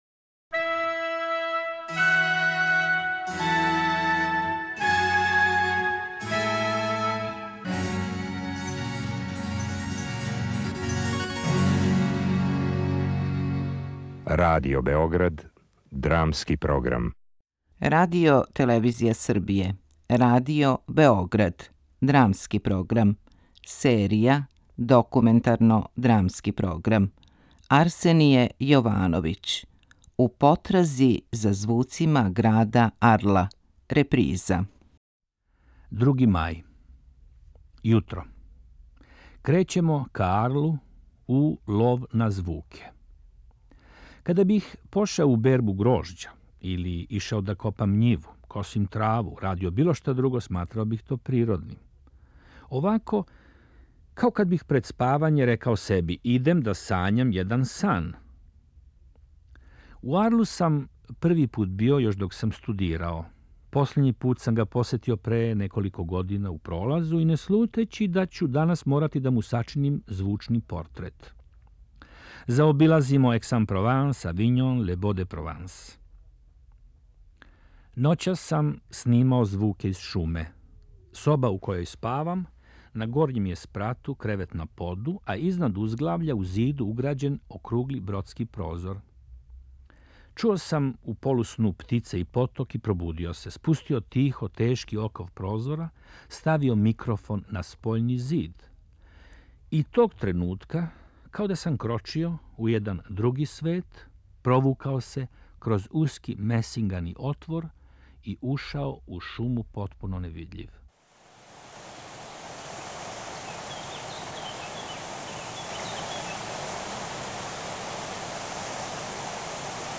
У овој документарној и аутопоетској белешци, аутор као наратор отвара слушаоцу звучну слику провансалског града Арла, фасциниран појавом мистрала, ветра специфичног за ову регију.
Документарно-драмски програм
У етнографском музеју Арсеније Јовановић снима и звуке меденице.